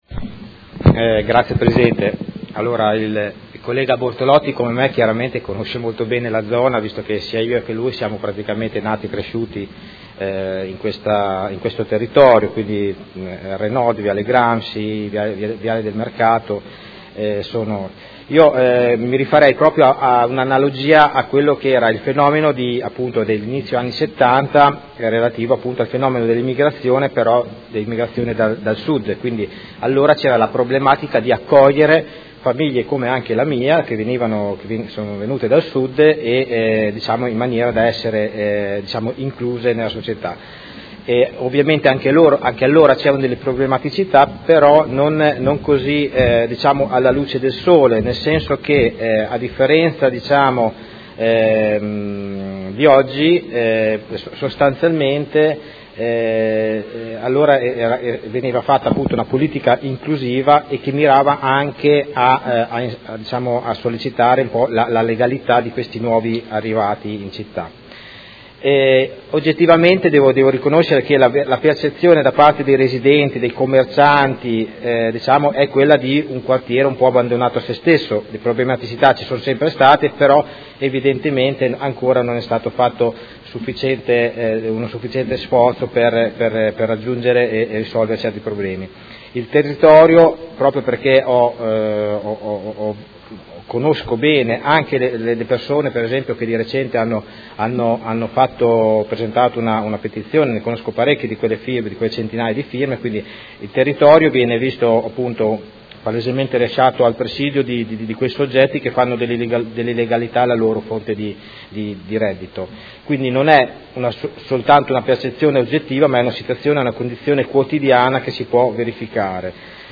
Vincenzo Walter Stella — Sito Audio Consiglio Comunale
Dibattito su interrogazione dei Consiglieri Venturelli, Stella e Carpentieri (P.D.) avente per oggetto: Problematiche zona R-nord - Viale Gramsci – e Parco XII Aprile. Quali azioni messe in campo dall’Amministrazione?